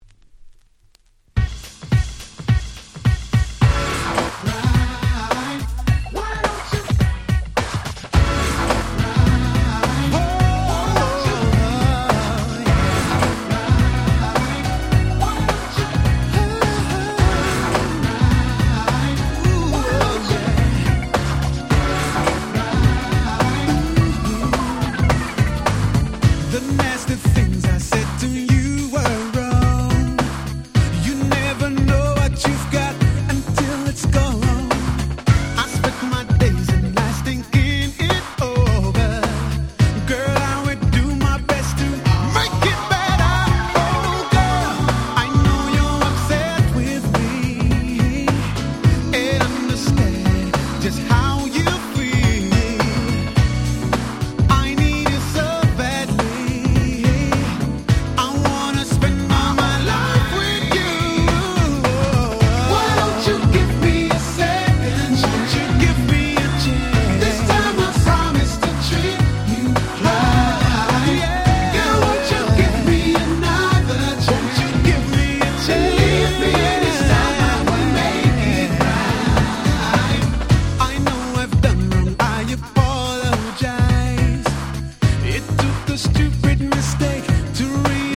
Super Nice UK R&B !!